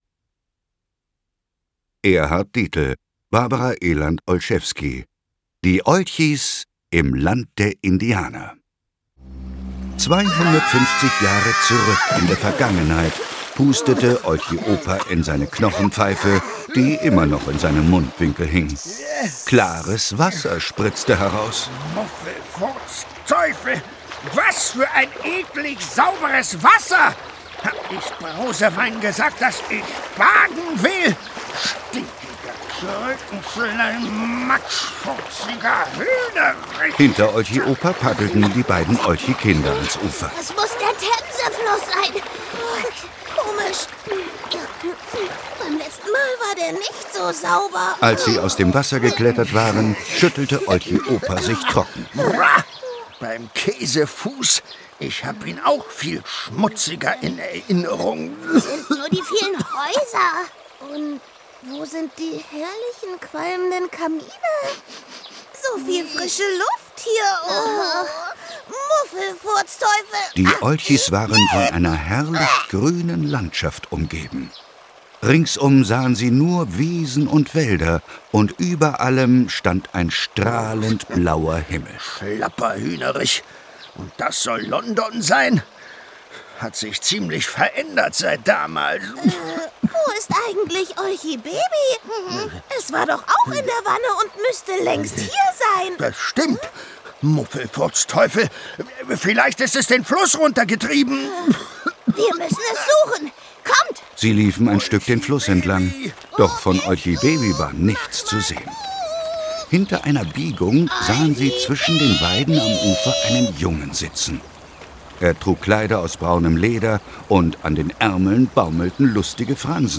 Hörspiel, ca. 62 Minuten Krötiger als Winnetou!